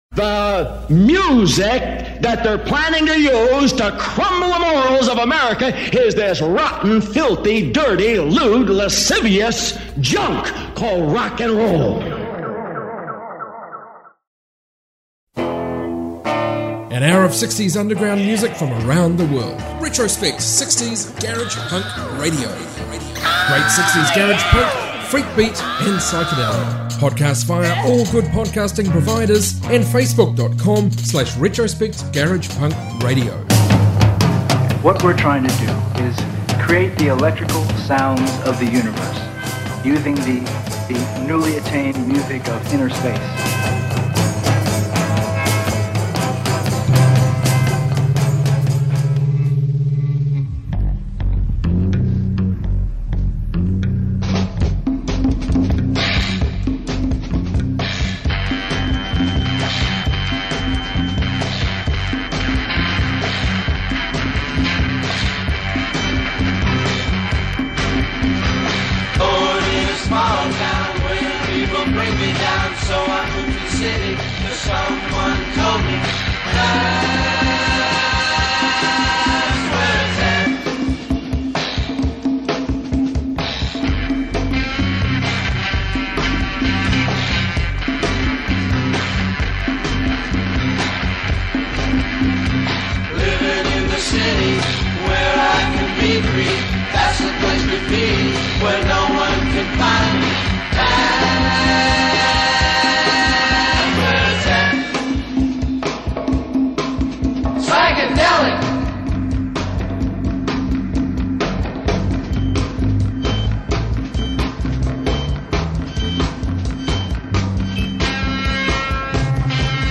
60s garage rock podcast